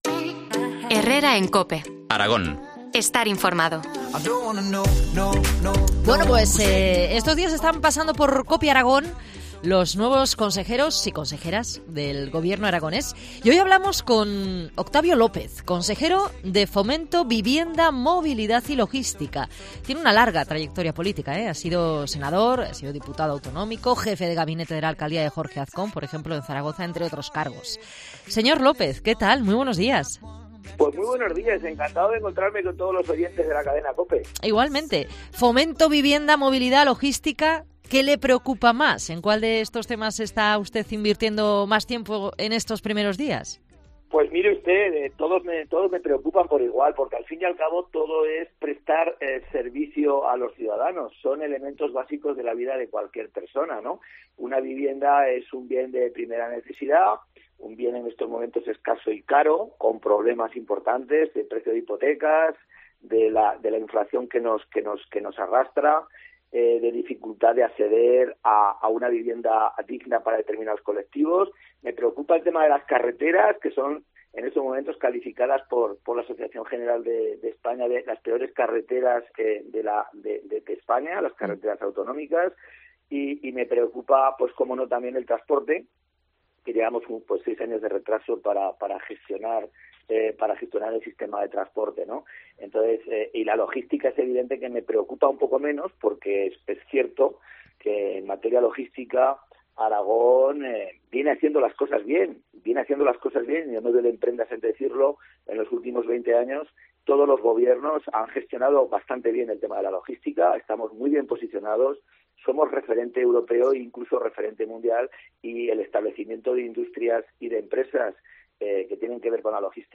Entrevista al consejero de Fomento, Vivienda y Movilidad del Gobierno de Aragón, Octavio López.